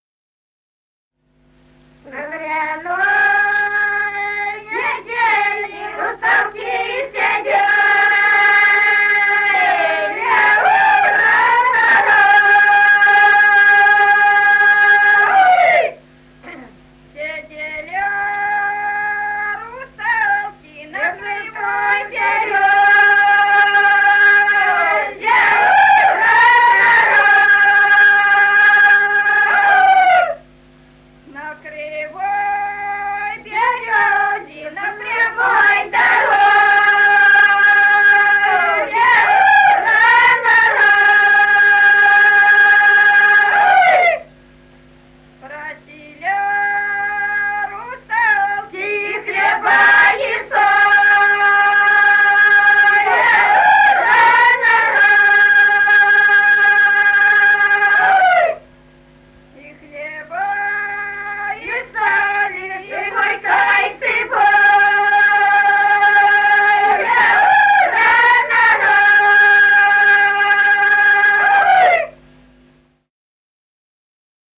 Народные песни Стародубского района «На гряной неделе», гряная.
с. Курковичи.